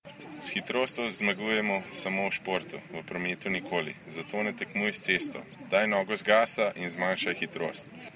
Da je od hitrosti pomembnejša preudarnost, meni tudi eden od najuspešnejših slovenskih športnikov, ki zase pravi tudi, da s hitrostjo zmaguje samo v športu, nikoli na cesti.
Veš, čigav je glas?